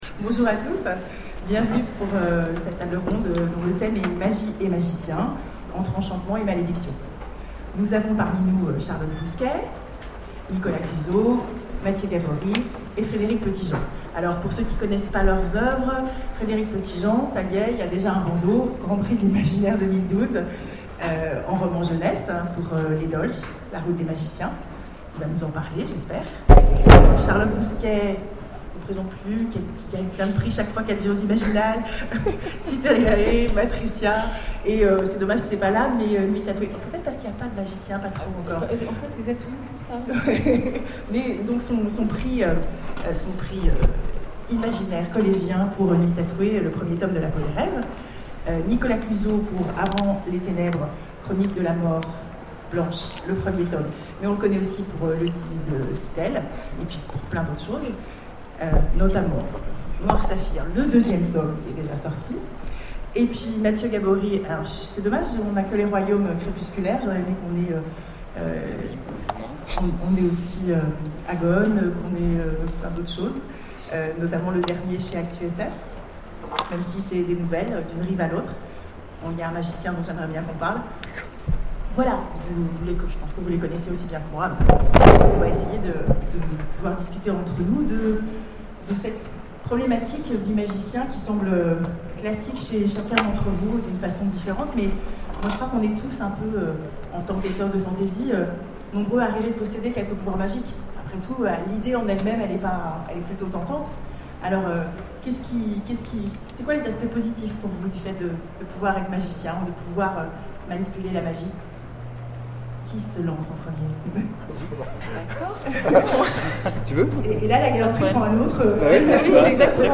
Imaginales 2012 : Conférence Magie et magiciens...